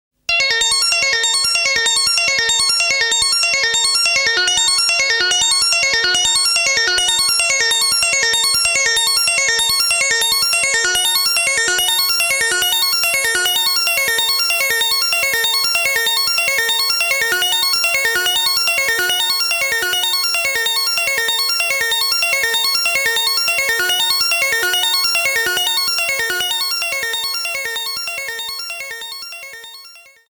Electro Electronix Wave